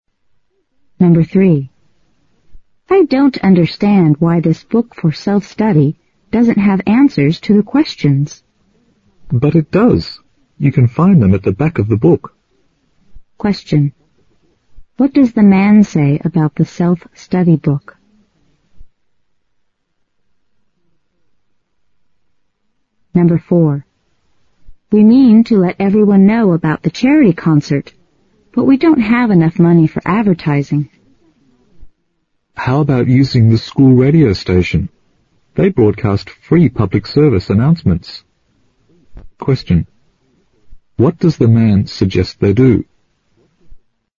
新编六级听力短对话每日2题 第129期